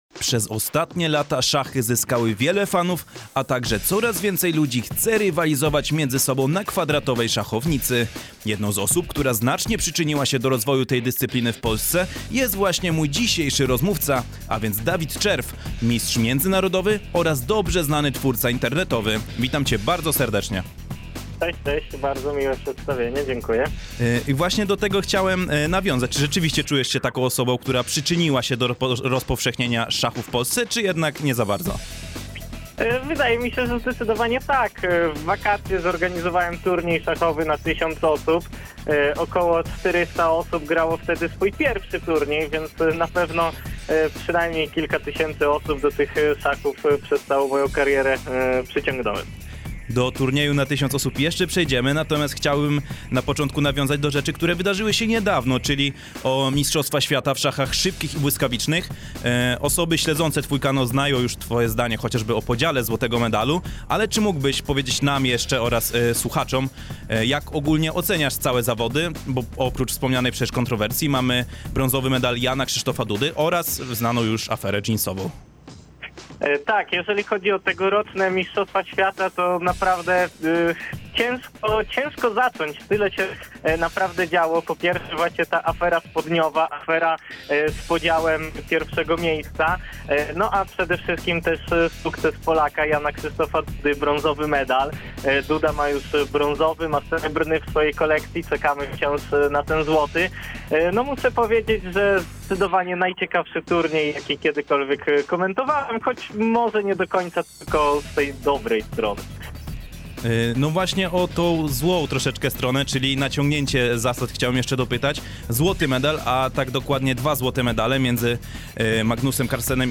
Podczas naszego programu „Dookoła Sportu” porozmawialiśmy z naszym gościem między innymi o ostatnich Mistrzostwach Świata, rozwoju polskich szachów oraz klubie Cebularz Lublin, który niedawno świętował swoją pierwszą rocznicę utworzenia. Zapis całej rozmowy znajduję się poniżej.